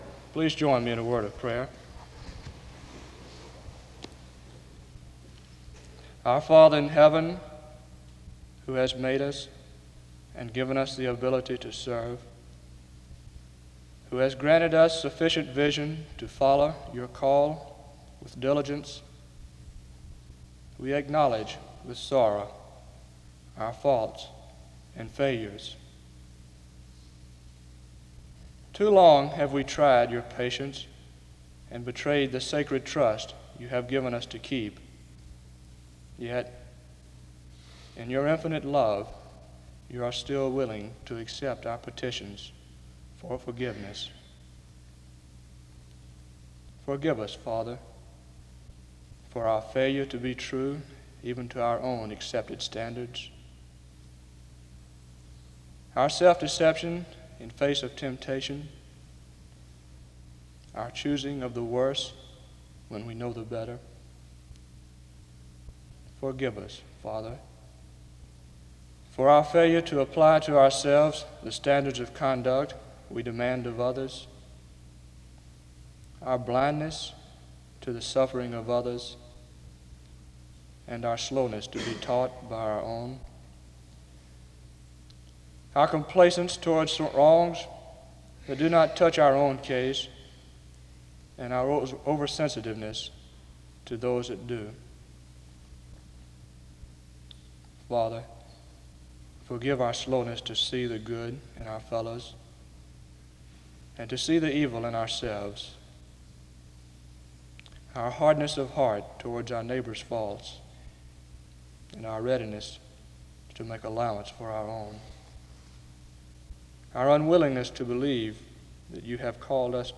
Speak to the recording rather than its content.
This service was organized by the Student Coordinating Council. In Collection: SEBTS Chapel and Special Event Recordings SEBTS Chapel and Special Event Recordings